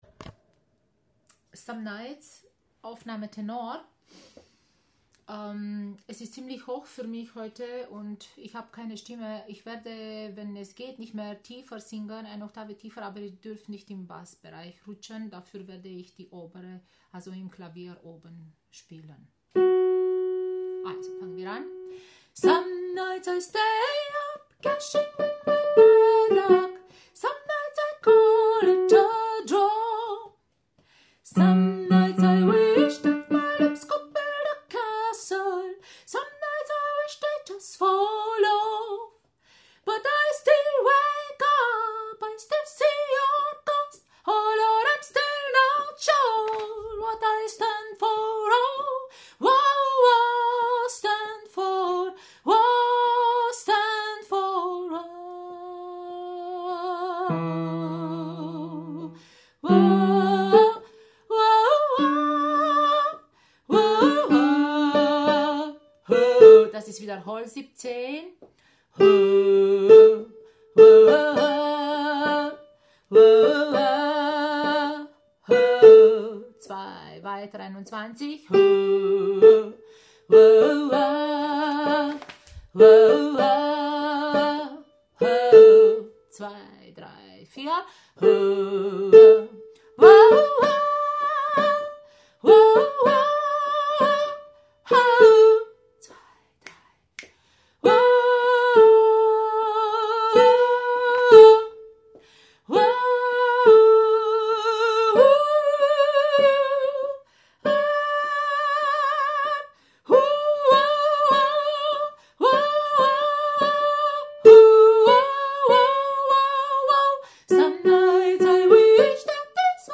Some Nights – Tenor